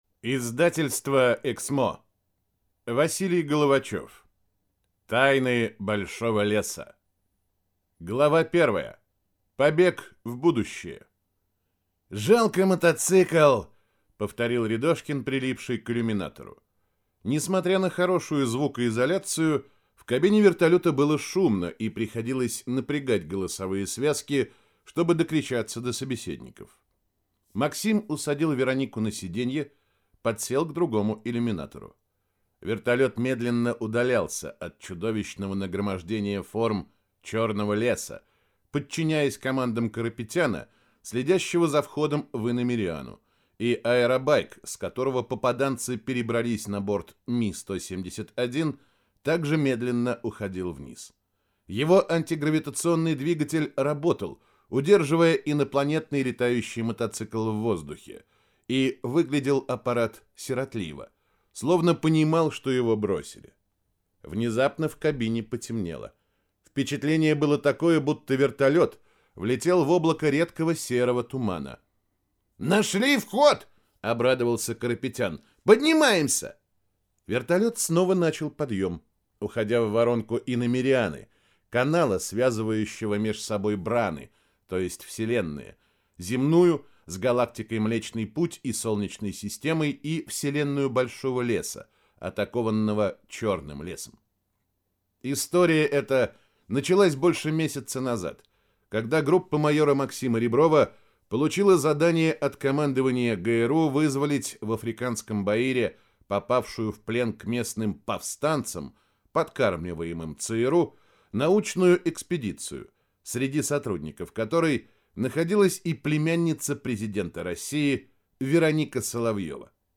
Aудиокнига Тайны большого леса